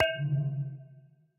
Minecraft Version Minecraft Version latest Latest Release | Latest Snapshot latest / assets / minecraft / sounds / block / end_portal / eyeplace1.ogg Compare With Compare With Latest Release | Latest Snapshot